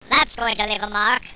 Index of /tactics/sfx/pain/squeaky